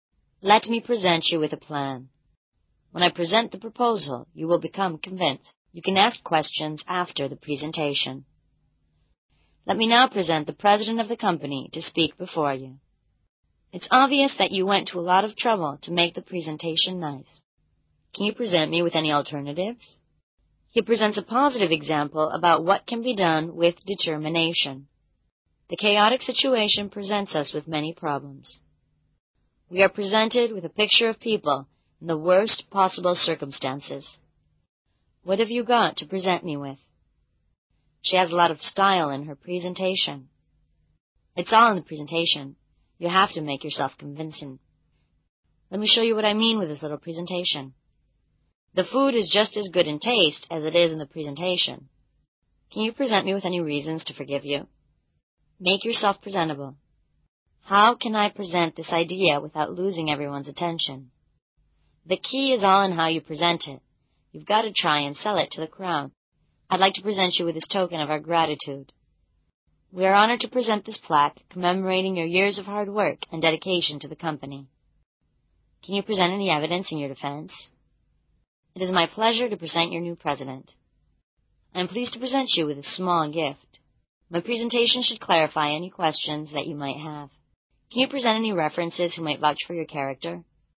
地道美语会话听力口袋丛书：（27）呈现